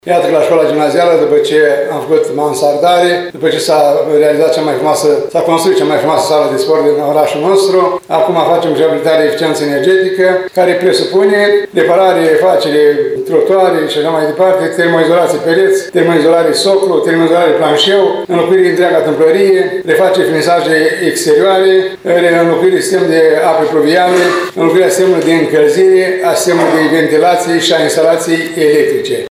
Primarul ION LUNGU a detaliat lucrările ce vor fi executate în următorii 2 ani.